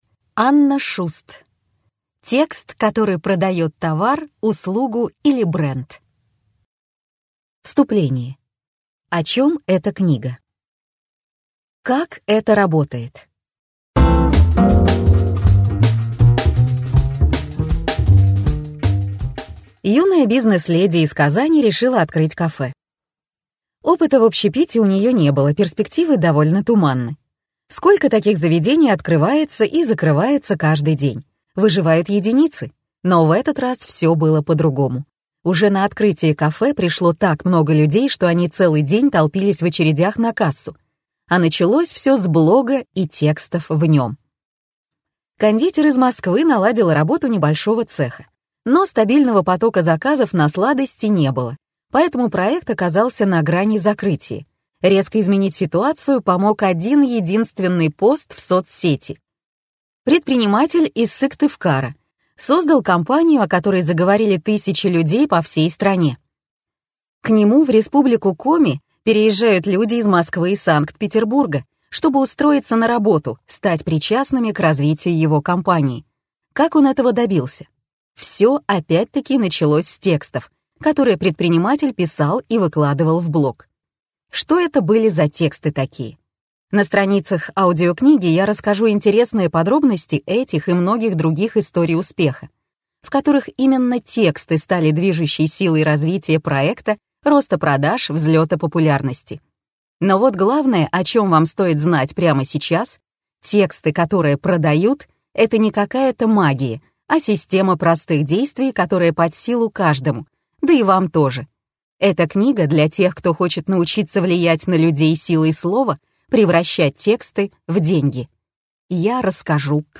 Прослушать фрагмент аудиокниги Текст